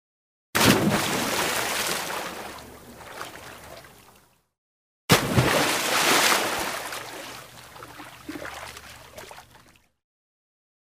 На этой странице собраны разнообразные звуки прыжков в воду: от легких всплесков до мощных ударов о поверхность.
Звуки стрибків у воду